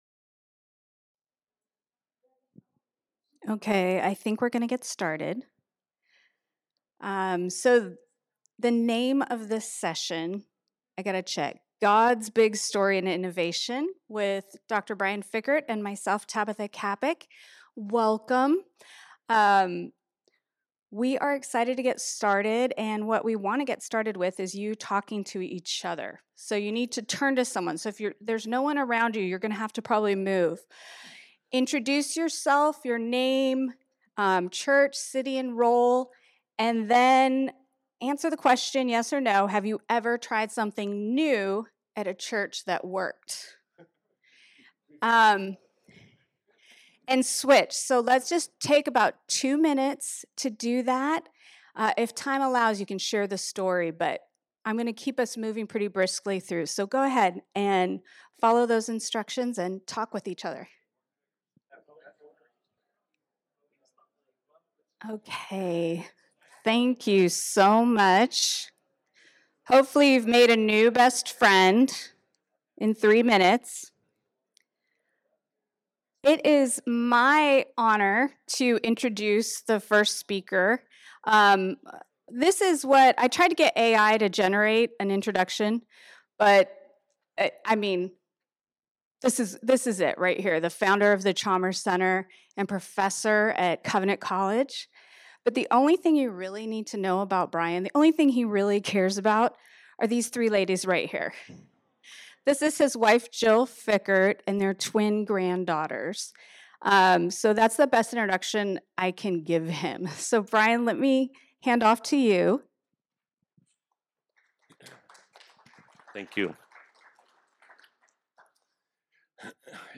General Assembly